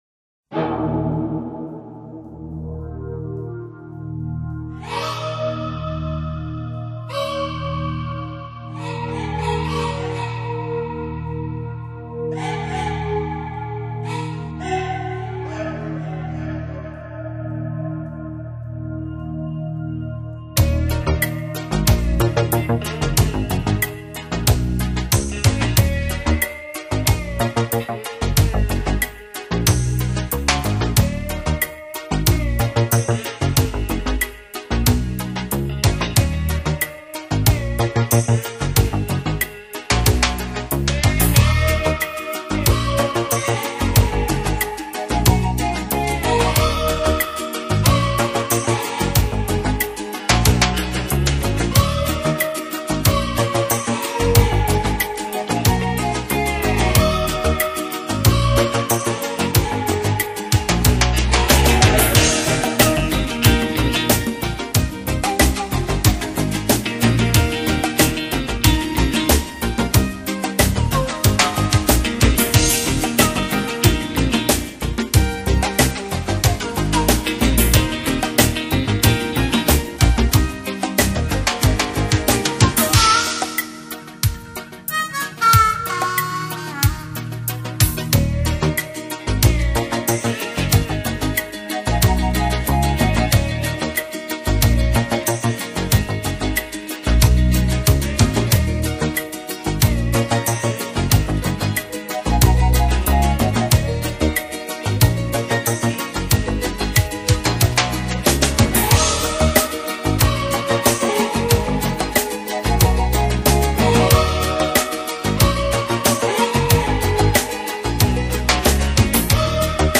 音乐类型：New Age
曼陀林，吉他
键盘，鼓
小提琴
Accordion.
口琴
Percussion.
萨克斯风
电吉他
低音吉他